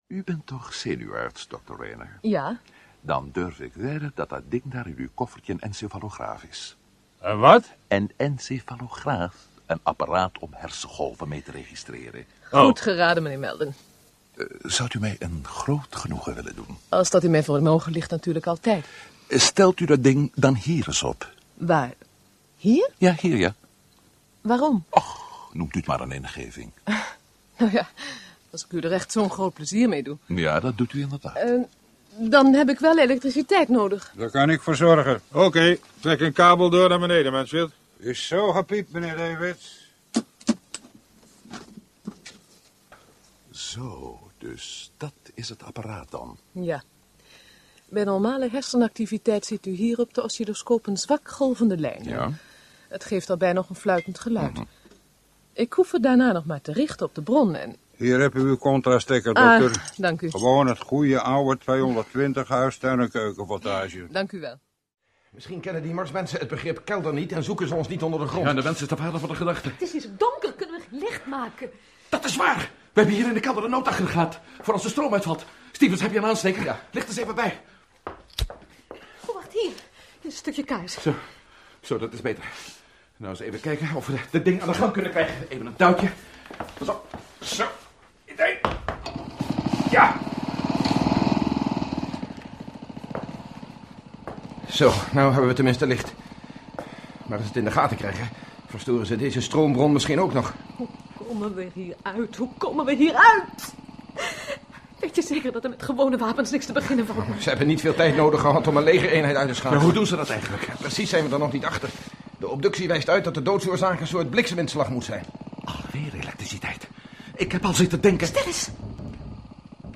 Hoorspel anders: Matt Meldon algemeen